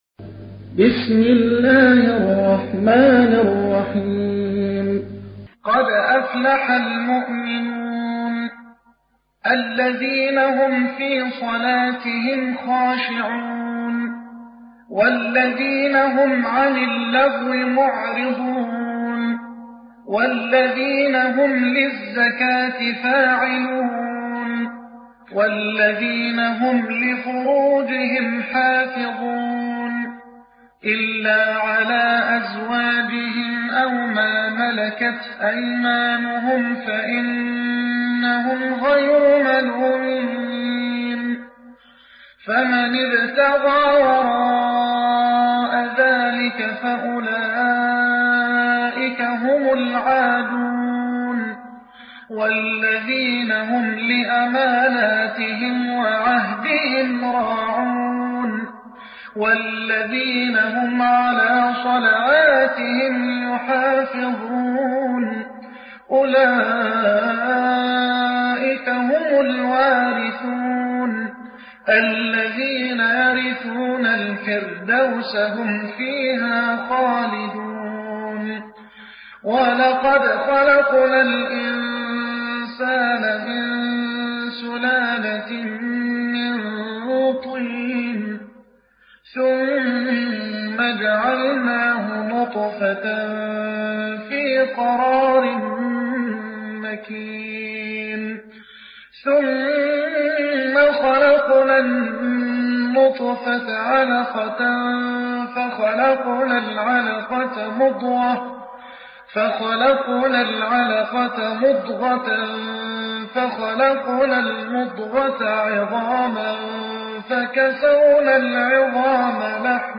تحميل : 23. سورة المؤمنون / القارئ محمد حسان / القرآن الكريم / موقع يا حسين